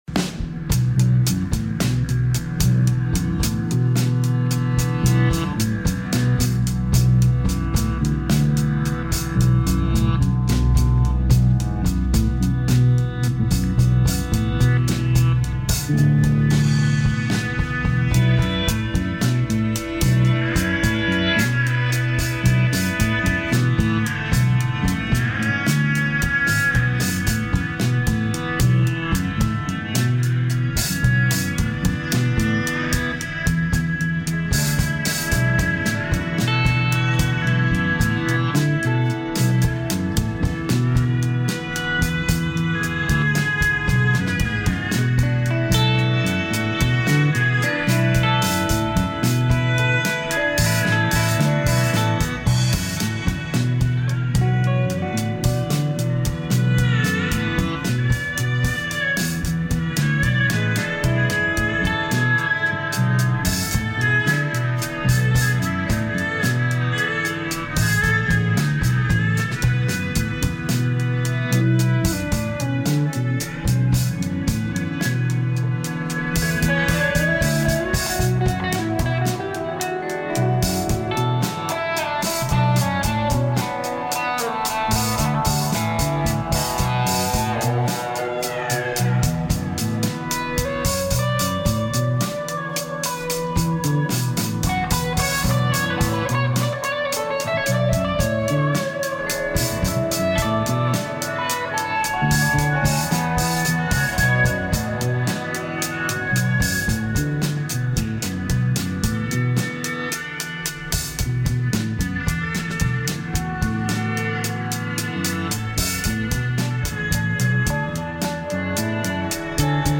Reading is Funktamental is a monthly one-hour show about great books written about music and music-makers.
Expect lively conversation with notables and a playlist of great music to go with it.